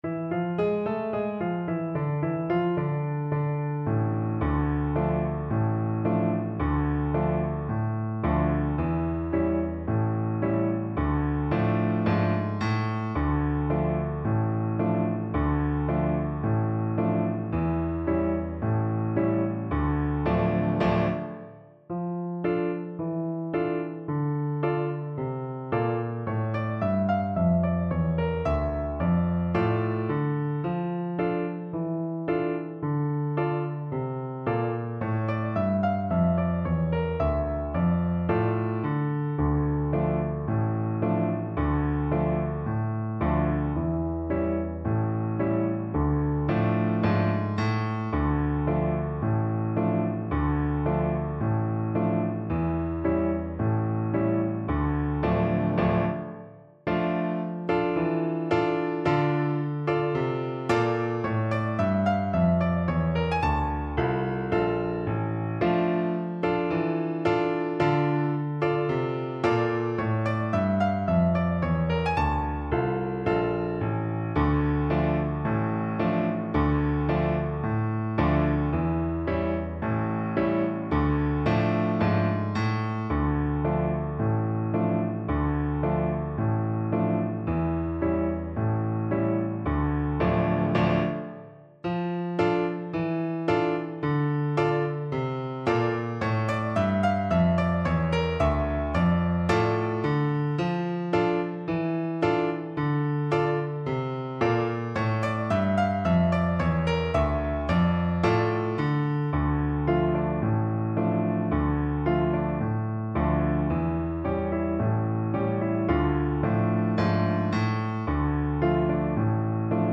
Play (or use space bar on your keyboard) Pause Music Playalong - Piano Accompaniment Playalong Band Accompaniment not yet available transpose reset tempo print settings full screen
Trumpet
D minor (Sounding Pitch) E minor (Trumpet in Bb) (View more D minor Music for Trumpet )
With energy =c.90
2/4 (View more 2/4 Music)
World (View more World Trumpet Music)